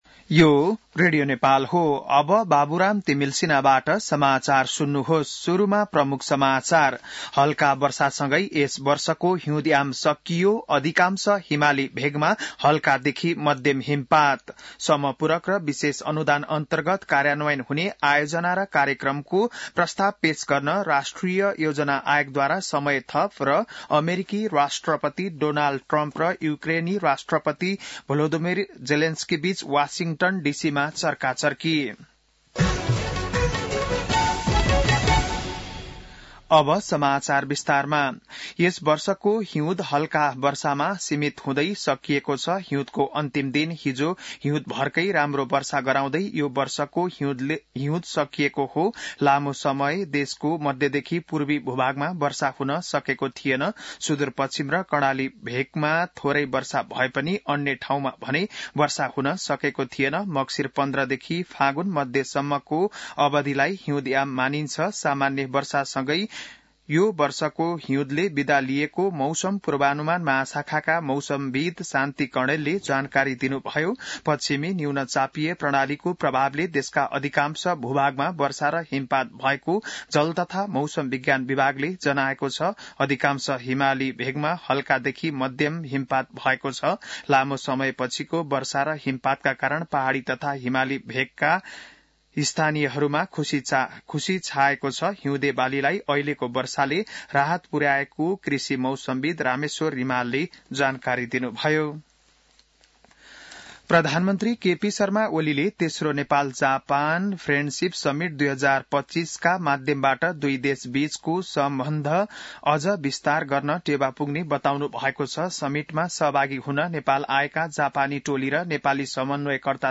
बिहान ९ बजेको नेपाली समाचार : १८ फागुन , २०८१